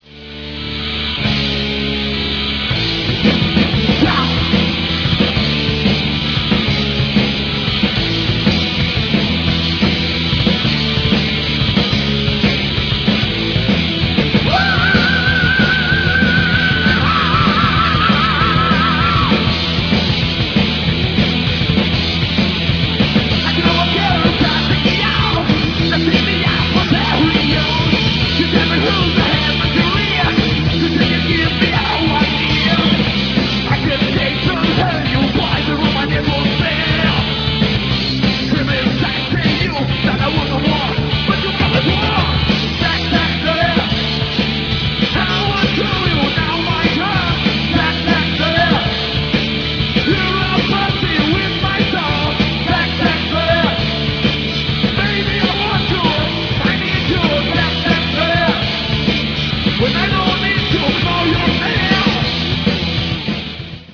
Third World Glam Rock Band